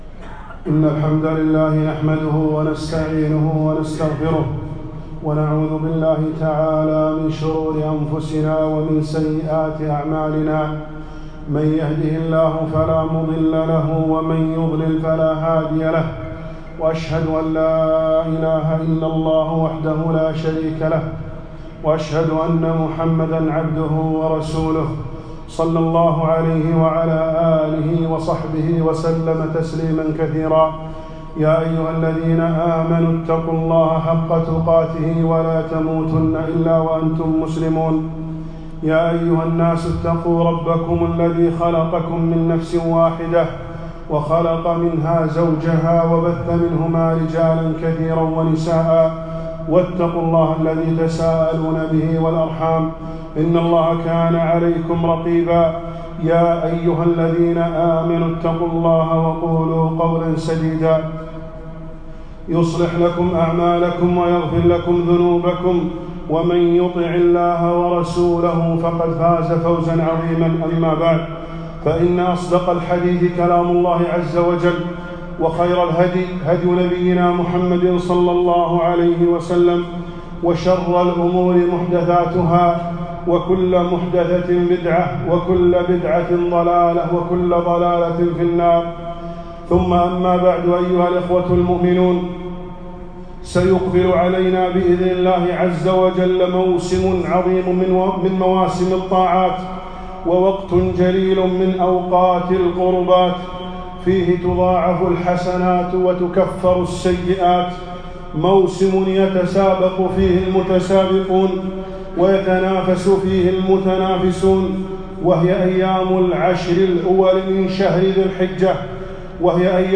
خطبة - العشر الأول من ذي الحجة فضائلها وأحكامها